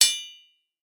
rock.ogg